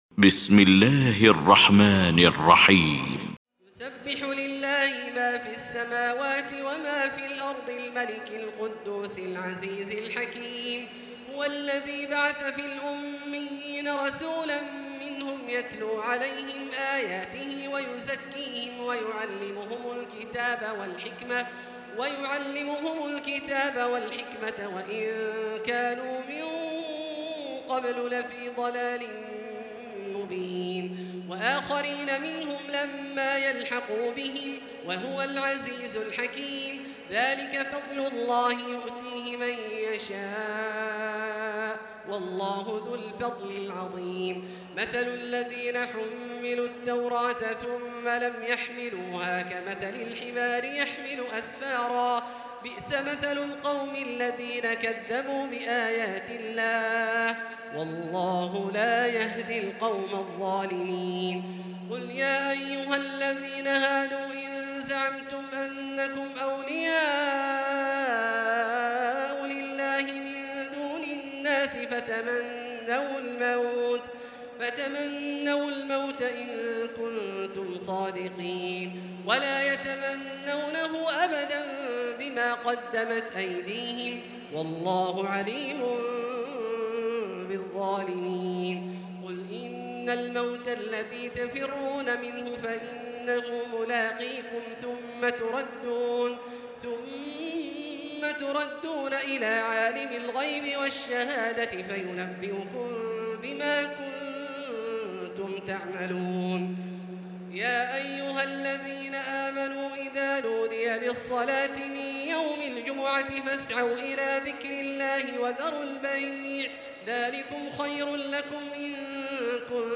المكان: المسجد الحرام الشيخ: فضيلة الشيخ عبدالله الجهني فضيلة الشيخ عبدالله الجهني الجمعة